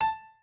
pianoadrib1_57.ogg